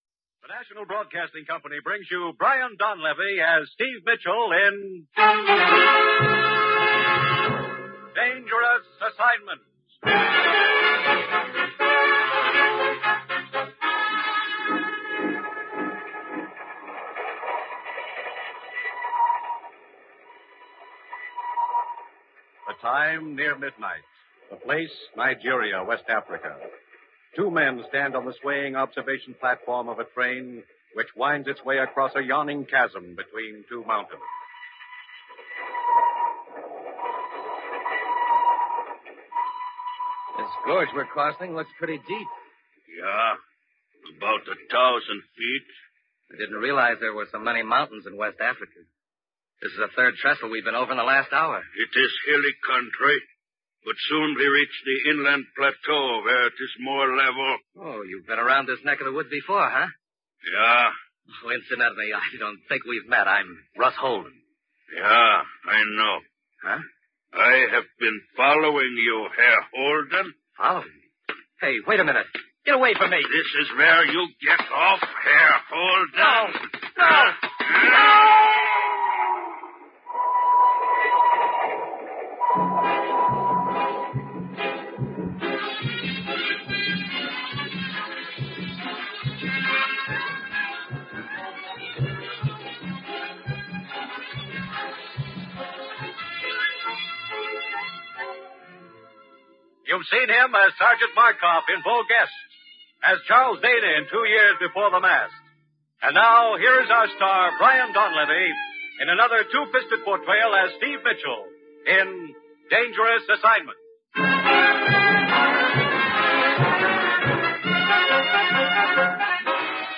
Here's a fun little deep-dive into the world of Dangerous Assignment, that slick old-time radio spy show starring the always-cool Steve Mitchell. If you're a fan of globe-trotting, double-crosses, and Cold War shenanigans, you're in for a treat.